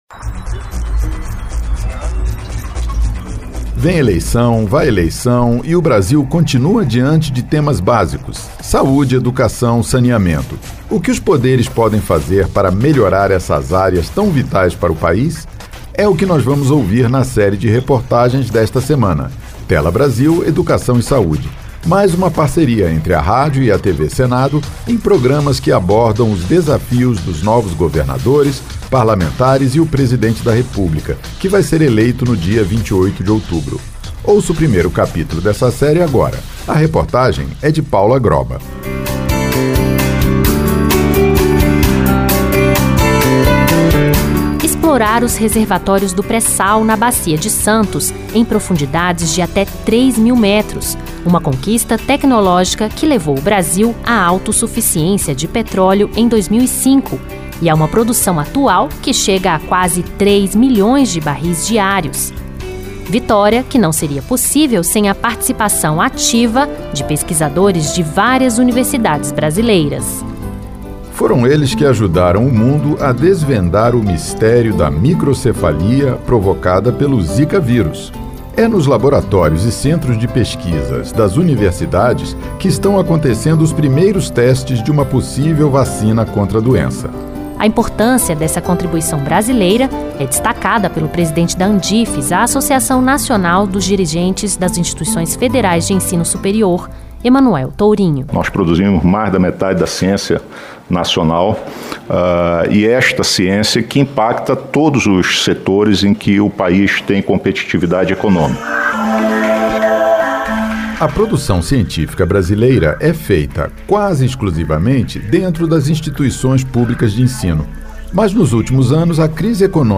Ouça o que especialistas dizem sobre isso na reportagem desta semana.